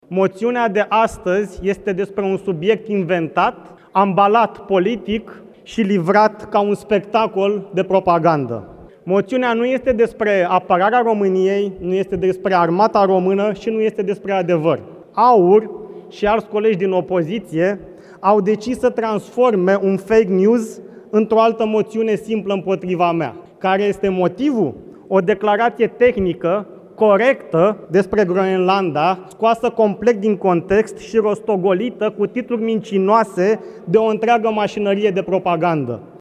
Ministrul Apărării, Radu Miruță: „Moțiunea nu este despre apărarea României, nu este despre armata română și nu este despre adevăr”